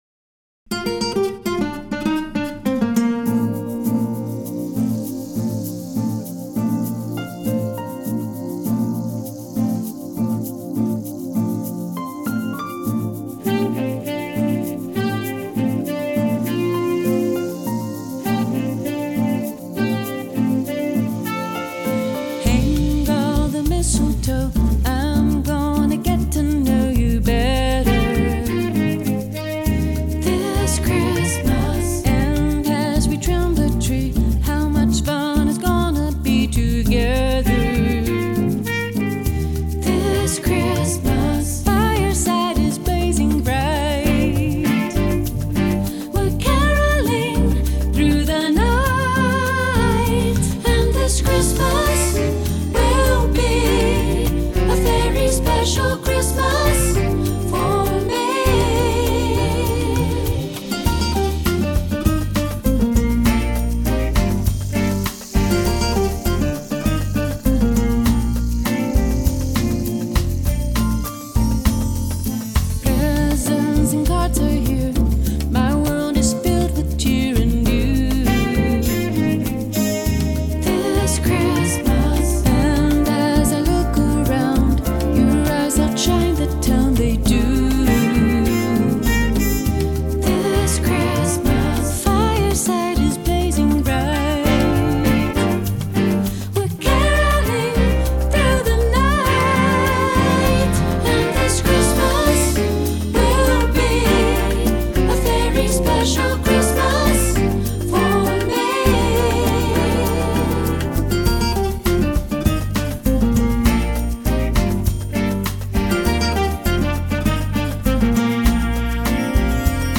Genre: Bossa Nova Christmas